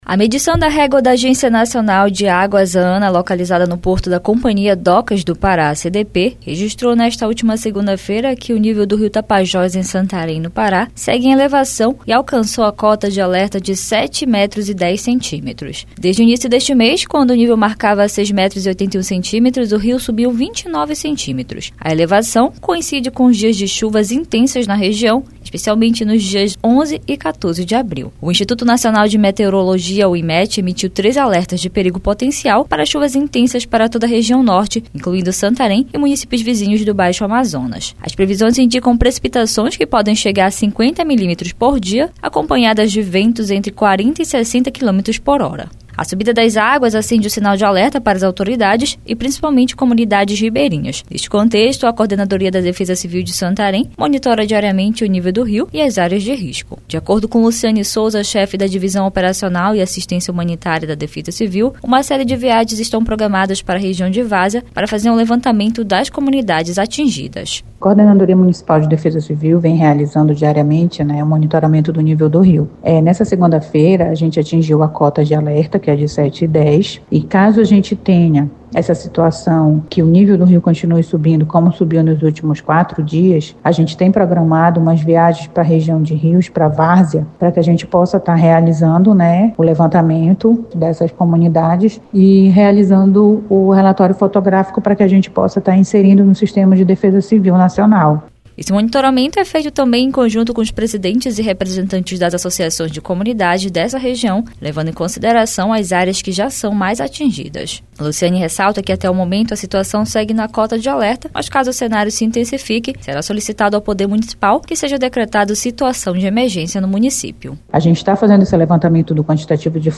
Segundo dados da Defesa Civil Municipal, desde o início do mês, o Rio subiu 29cm ultrapassando 7,10 metros. A reportagem